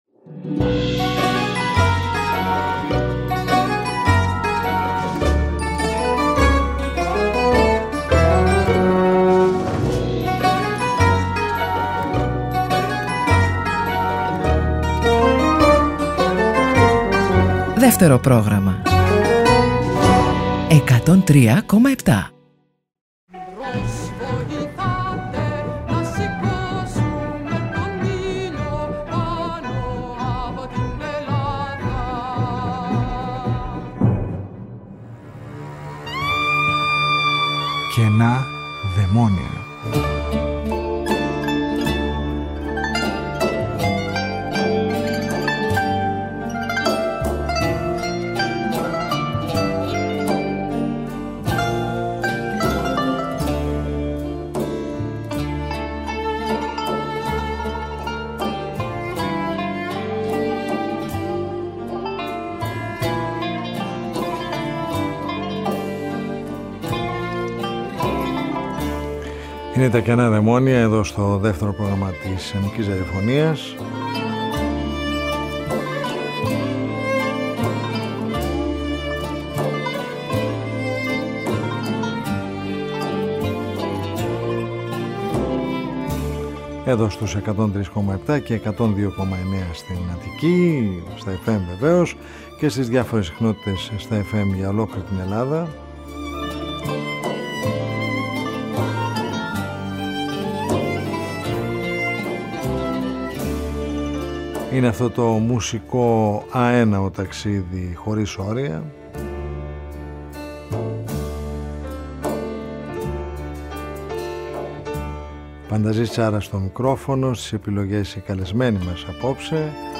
Ήχοι με ιδιαίτερη ανατολίτικη χροιά που σε πλημμυρίζουν με νοσταλγία και ενώνουν το παρελθόν με το παρόν, ταξιδεύοντας μας σε αλλοτινούς χρόνους και μέρη.
Συνεντεύξεις